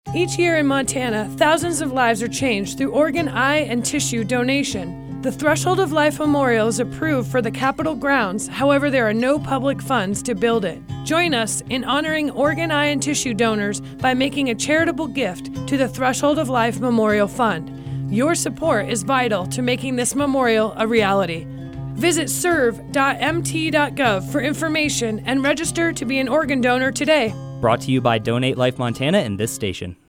Public Service Announcements
Radio Spots